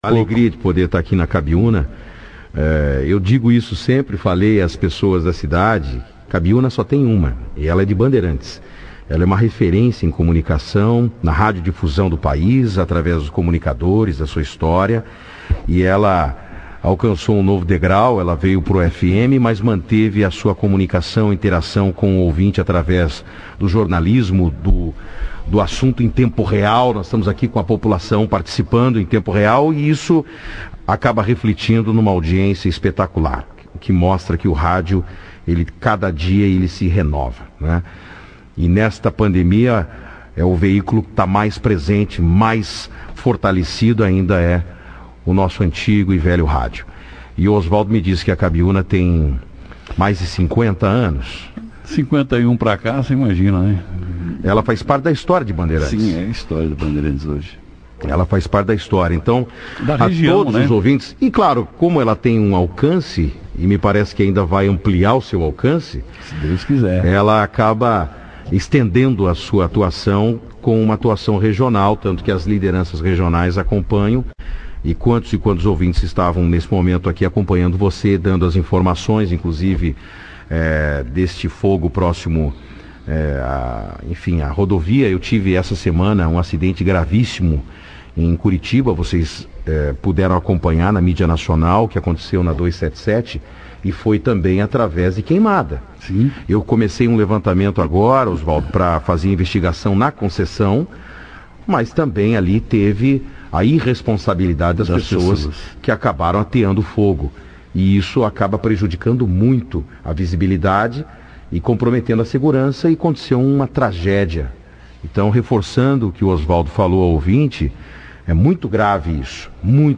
O secretário também participou ao vivo da 2ª edição do jornal Operação Cidade, falando sobre o trabalho desenvolvido pela sua secretaria na região, principalmente da recuperação da malha asfáltica, realizadas entre Bandeirantes e Santa Amélia (PR-436), ligação Bandeirantes a Thermas Yara (Rodovia Tsuneto Matsubara), em Itambaracá trechos que Porto Almeida (PR-436) e a São Joaquim do Pontal (PR-517).